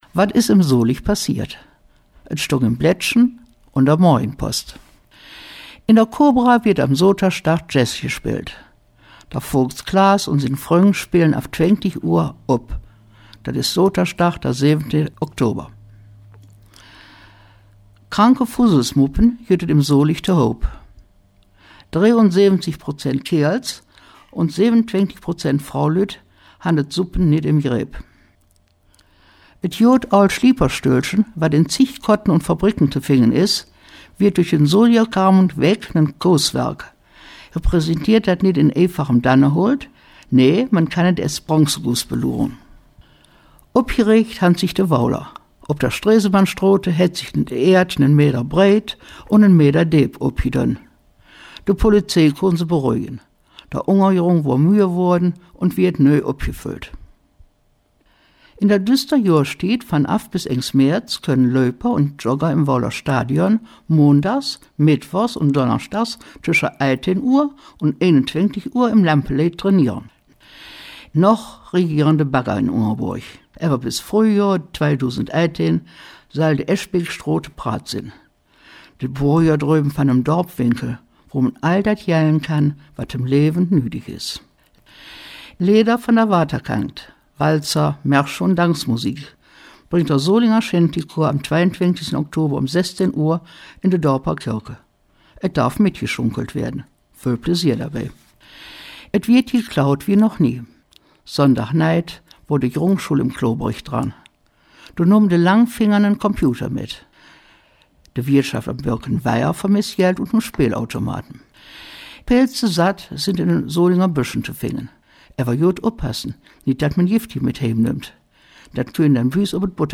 Die Folgen der Nachrichten in Solinger Platt aus 2017:
40-KW-Solinger-Platt-News.wav